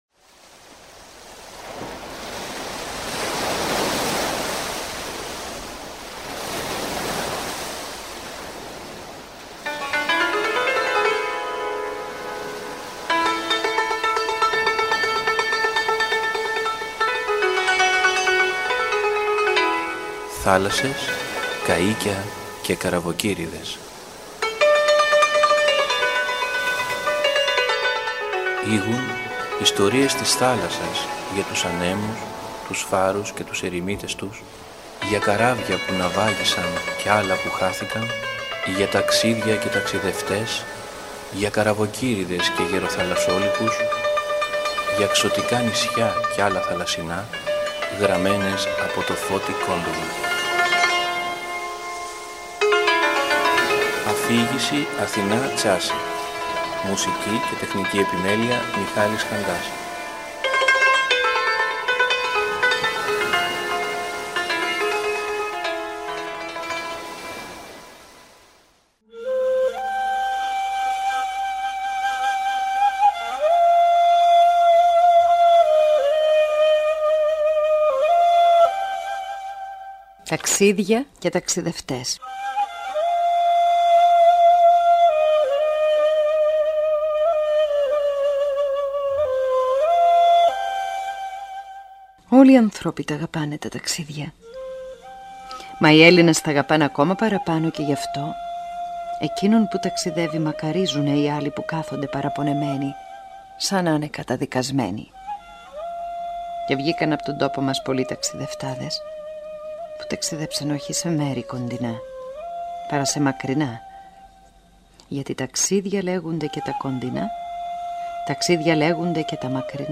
Αφήγηση